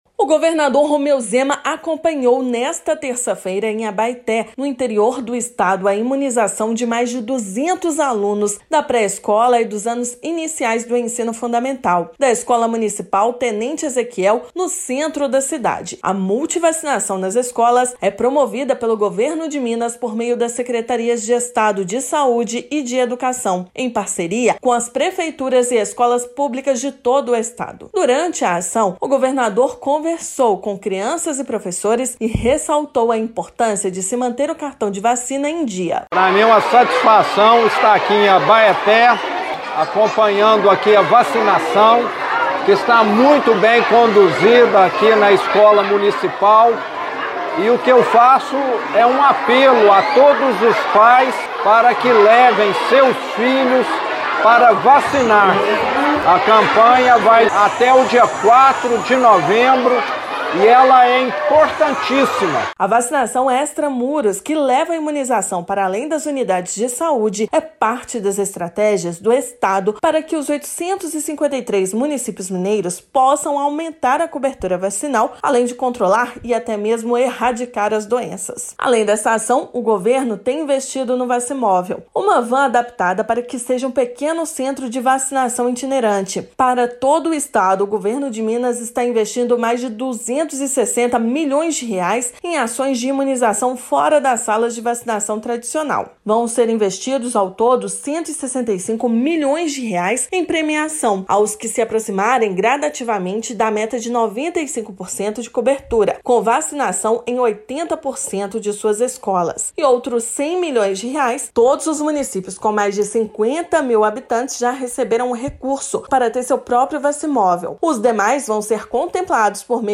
Em Abaeté, governador acompanhou imunização de estudantes dentro da Escola Municipal Tenente Ezequiel; campanha em todo o estado para atualização dos cartões segue até 4/11. Ouça matéria de rádio.